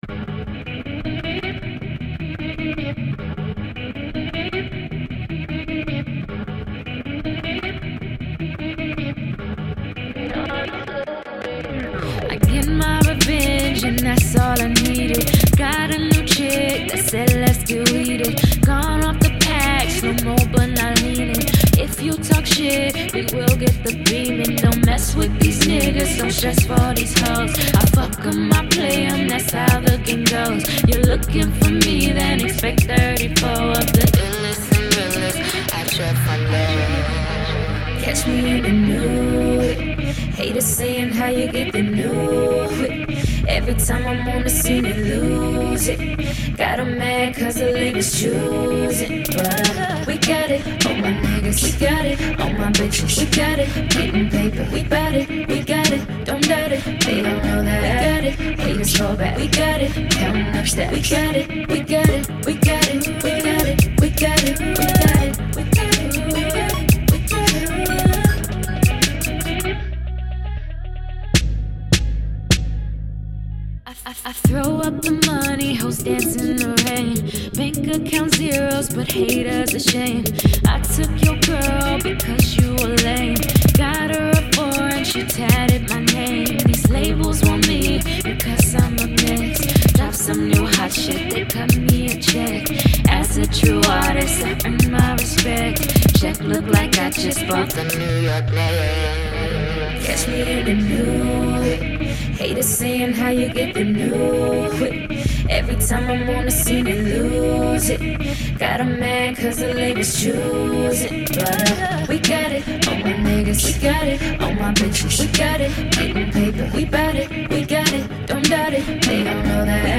RnB
R&B track with some dope ass Hip-Hop flava!